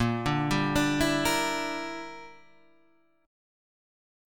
Bb6b5 chord